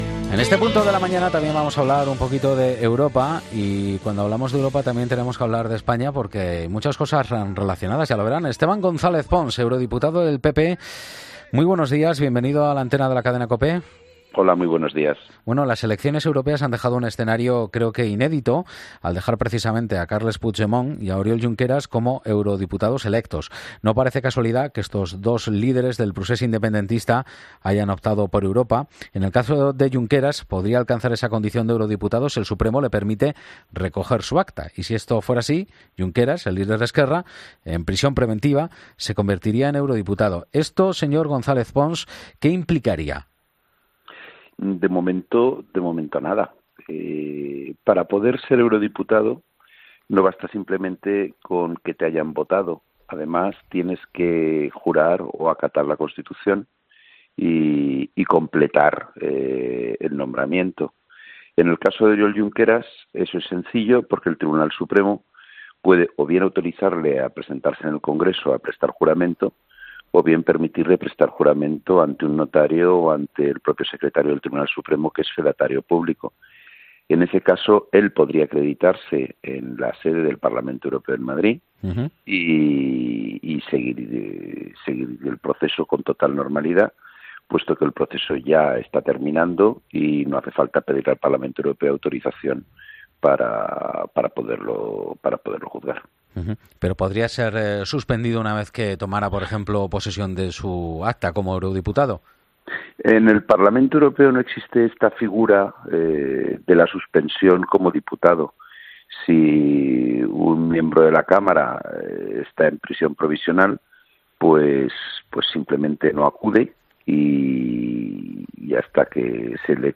El eurodiputado del PP analiza en COPE el resultado de las elecciones europeas y asegura que el PSOE no ha empezado bien las negociaciones